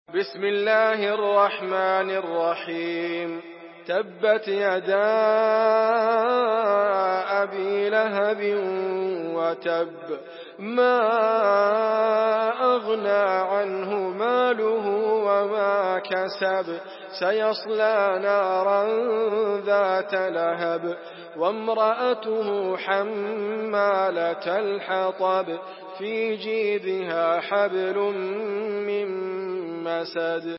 Surah المسد MP3 by إدريس أبكر in حفص عن عاصم narration.
مرتل حفص عن عاصم